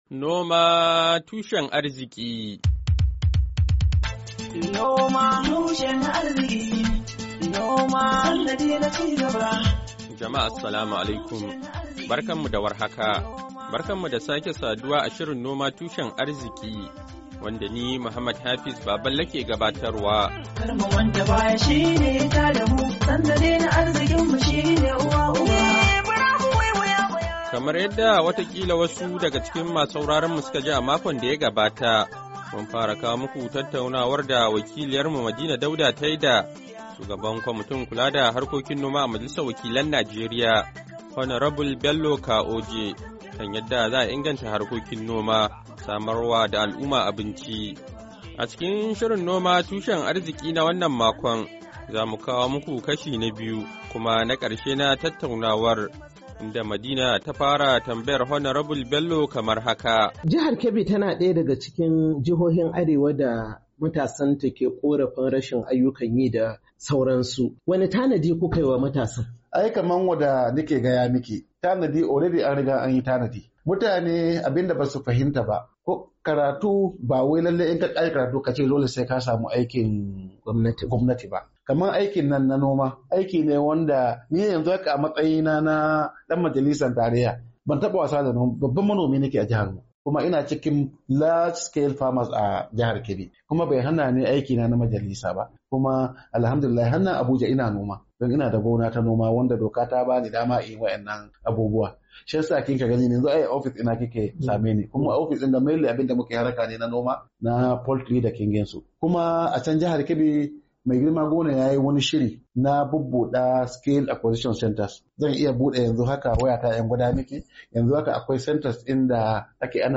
NOMA TUSHEN ARZIKI: Hira Da Shugaban Kwamitin Kula Da Harkokin Noma Na Majalisar Wakilan Najeriya, Kashi Na Biyu, Fabrairu 27, 2024